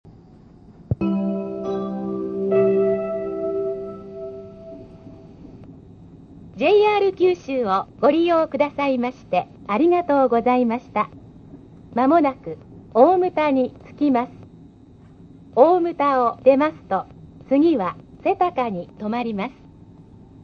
「つばめ」時代の大牟田到着時の放送。